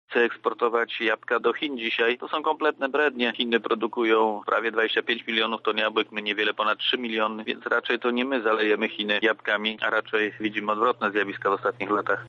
Nie podlega dyskusji, że polski rząd jest jak zwykle zaskoczony – komentuje Szewczak